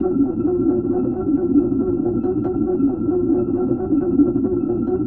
Engines of the Future Demo
Future_engine_31_On.wav